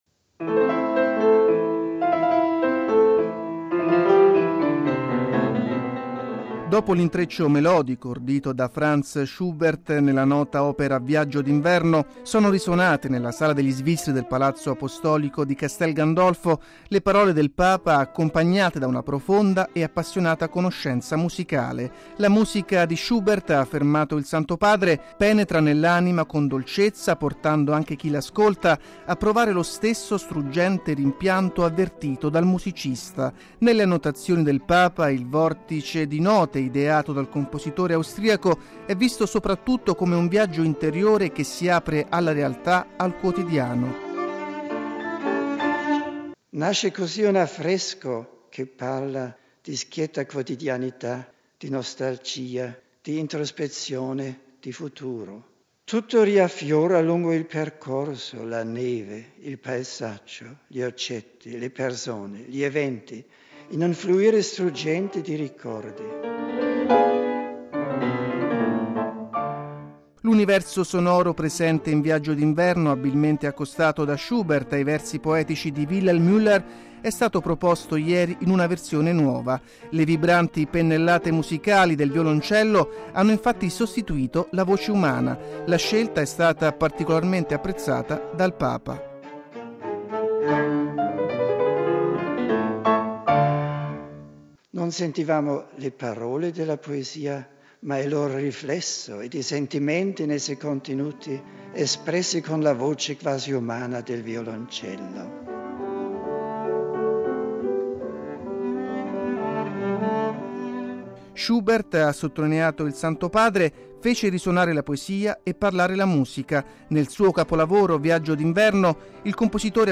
(musica)
L’universo sonoro presente in “Viaggio d’inverno”, abilmente accostato da Schubert ai versi poetici di Wilhelm Müller, è stato proposto ieri in una versione nuova: le vibranti pennellate musicali del violoncello hanno infatti sostituito la voce umana.